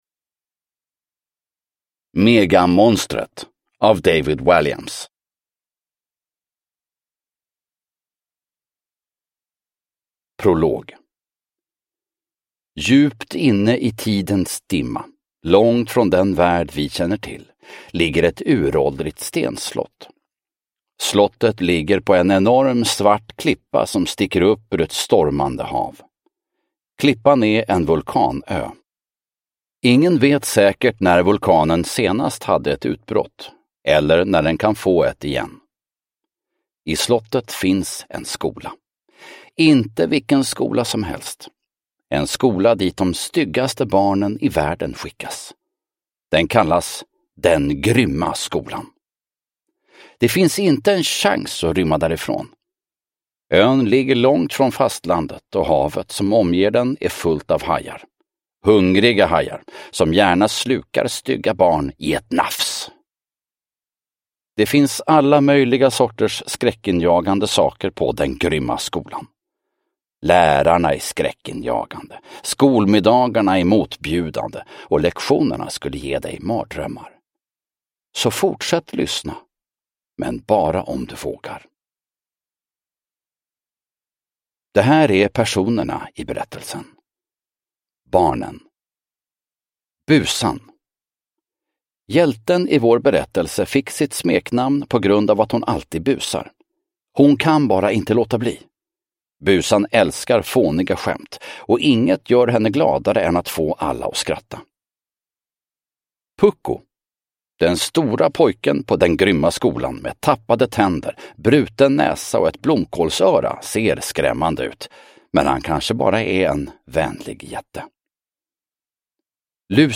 Megamonstret – Ljudbok – Laddas ner
Uppläsare: Fredde Granberg